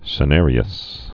(sə-nârē-əs)